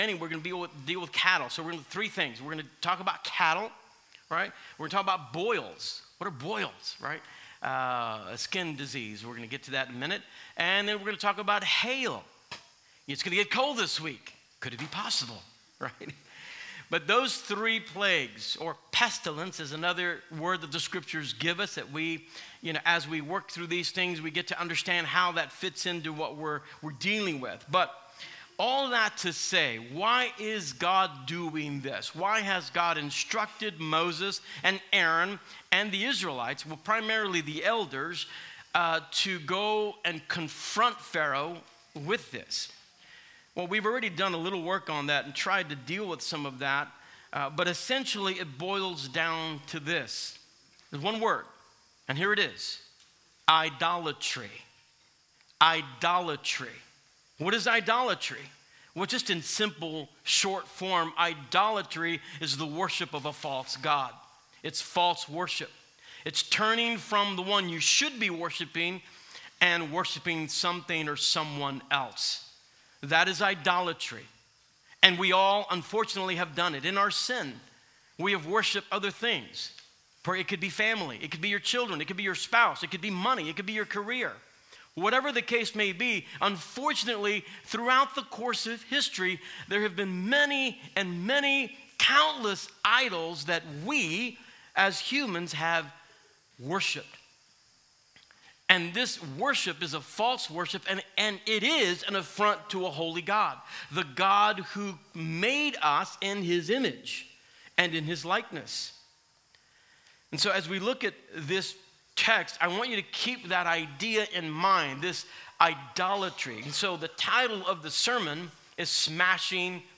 Sermons | Christ Redeemer Church